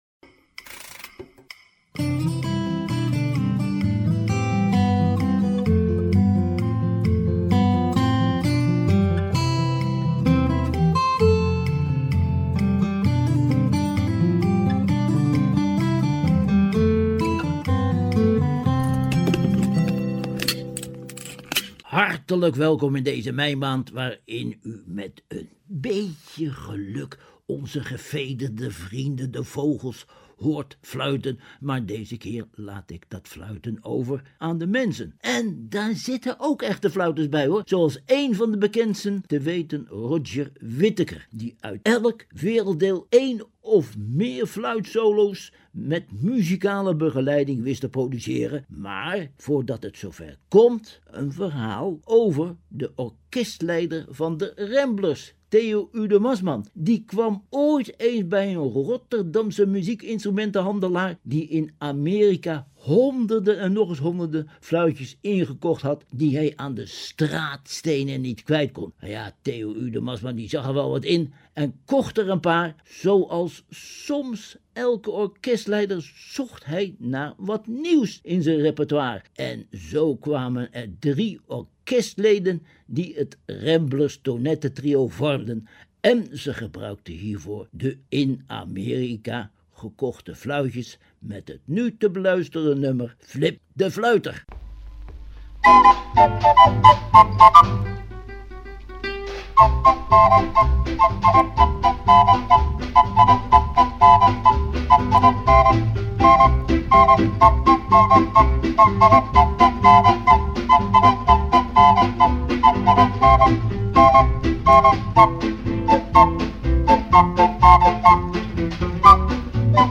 een fluit met beperkte mogelijkheden